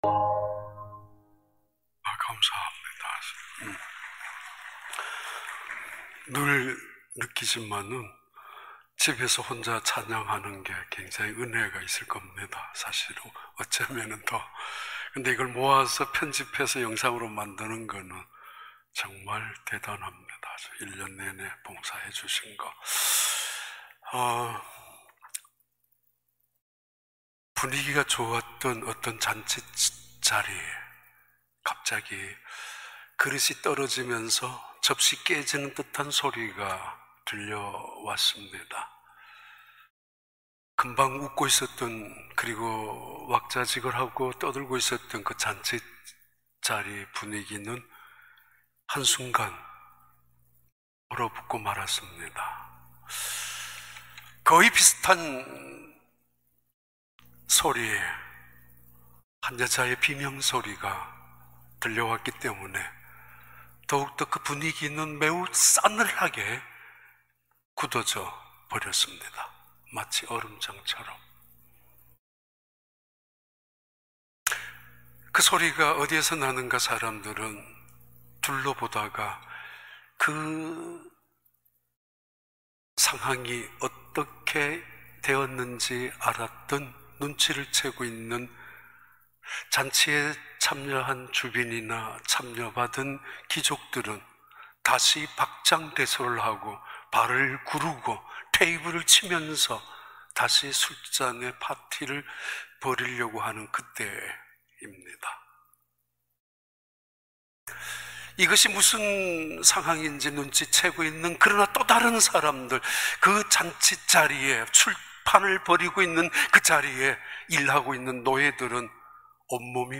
2021년 1월 24일 주일 4부 예배